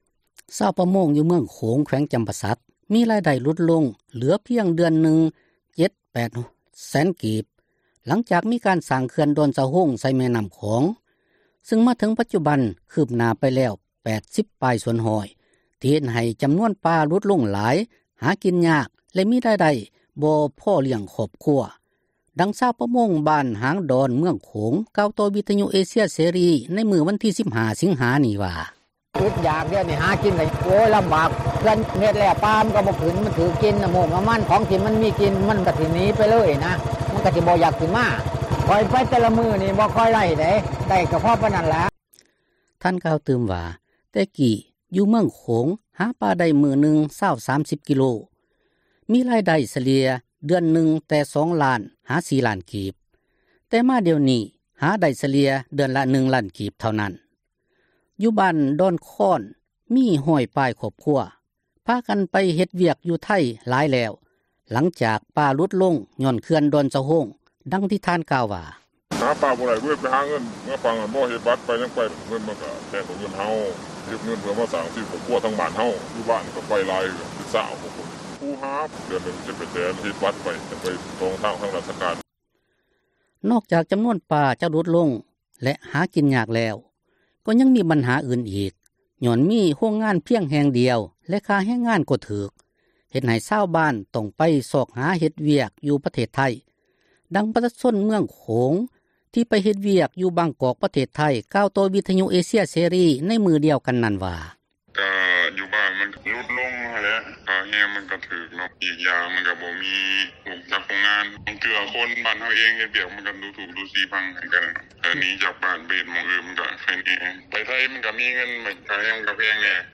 ຊາວປະມົງຢູ່ເມືອງໂຂງ ແຂວງຈຳປາສັກ ມີຣາຍໄດ້ຫລຸດລົງເຫລືອພຽງ ເດືອນລະ 7-8 ແສນກີບ ຫລັງຈາກມີການສ້າງເຂື່ອນດອນສະໂຮງ ໃສ່ແມ່ນ້ຳຂອງ ຊຶ່ງມາເຖິງປັດຈຸບັນ ຄືບໜ້າໄປແລ້ວ 80 ປາຍ ສ່ວນຮ້ອຍ ທີ່ເຮັດໃຫ້ຈຳນວນປາຫຼຸດລົງຫຼາຍ ຫາກິນຍາກ ແລະມີຣາຍໄດ້ ບໍ່ພໍລ້ຽງຄອບຄົວS ດັ່ງຊາວປະມົງ ບ້ານຫາງດອນ ເມືອງໂຂງ ກ່າວຕໍ່ວິທຍຸເອເຊັຽເສຣີ ໃນມື້ວັນທີ 15 ສິງຫານີ້ວ່າ: